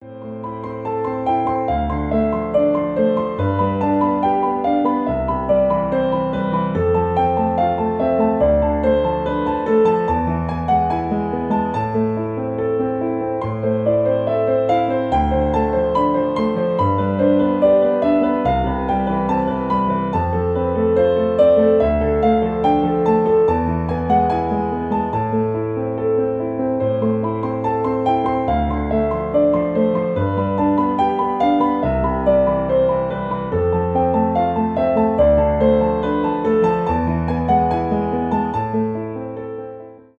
классические
без слов , пианино , инструментальные , красивая мелодия